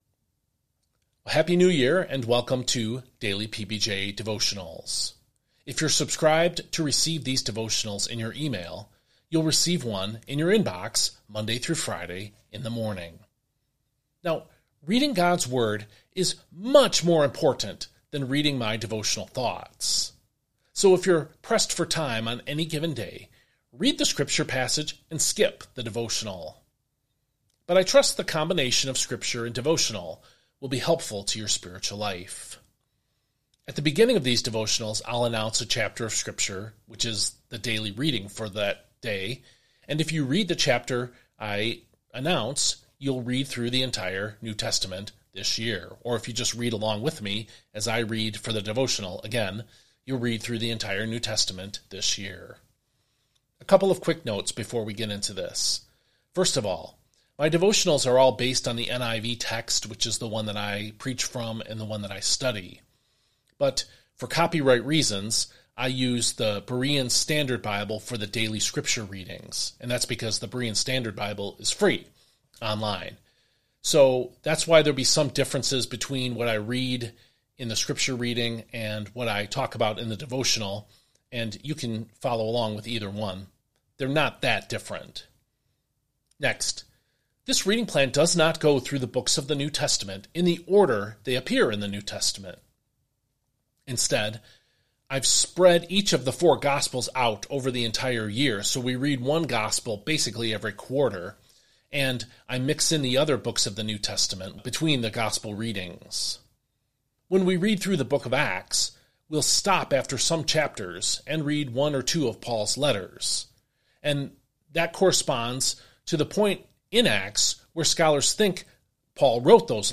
Listen or Watch: Listen to today’s Bible reading and devotional.